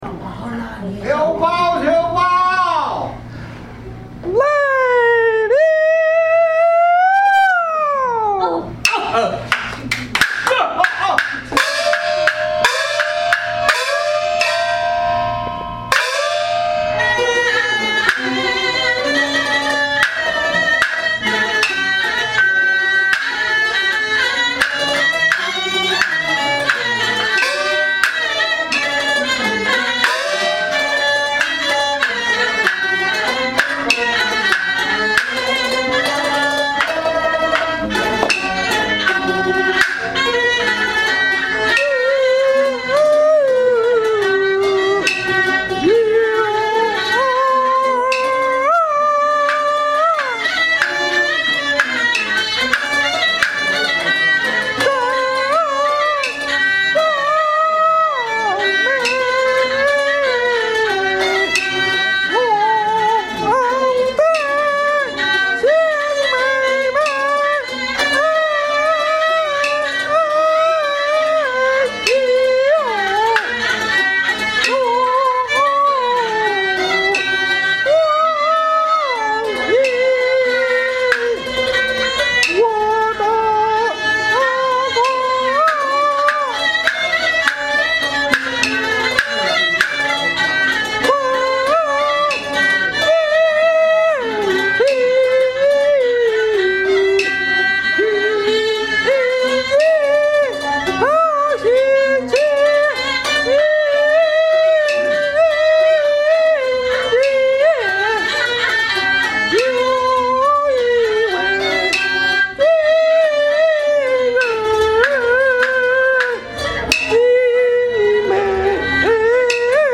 觉得上面我唱时控制较好，就是唱错一个地方，还有，缺二胡伴奏。
乐队伴奏：洛杉矶《中华之声国剧社》文场武场乐队